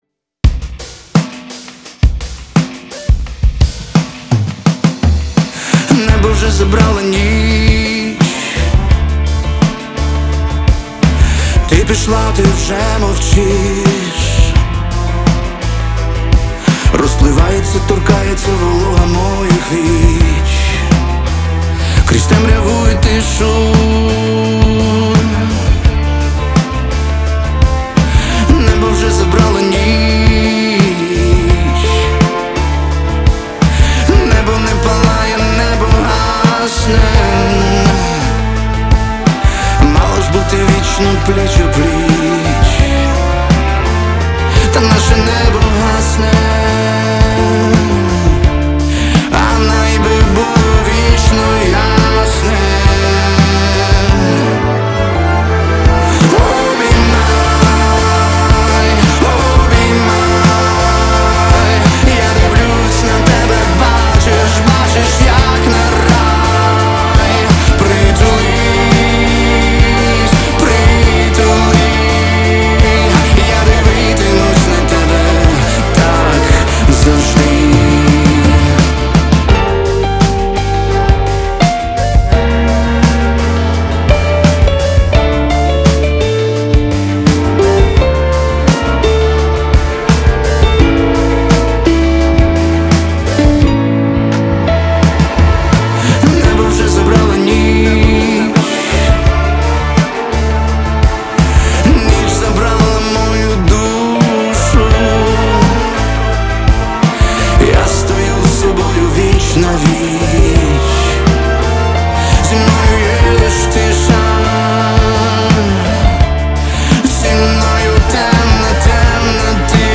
• Жанр:Рок